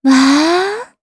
Xerah-Vox_Happy3_jp.wav